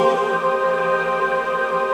ATMOPAD28.wav